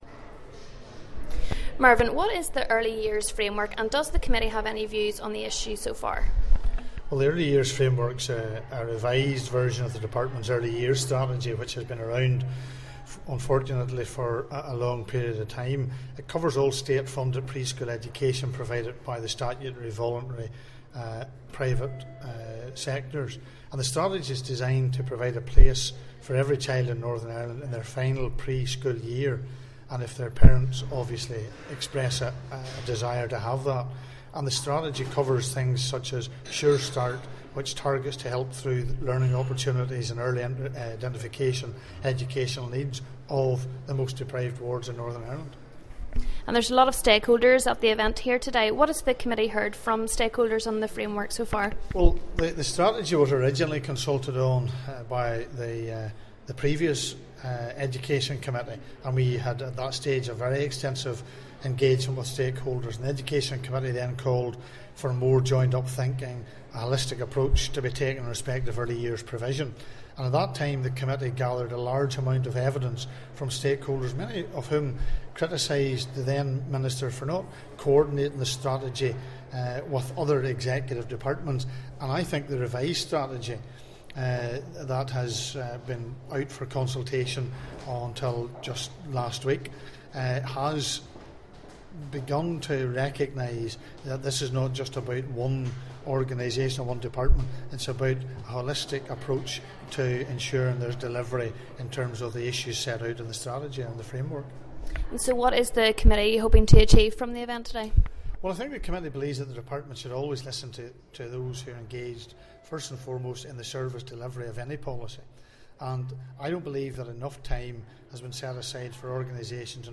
Committee for Education Chairperson Mr Mervyn Storey outlines what the Early Years Framework is and what the Committee learned at its recent stakeholder event.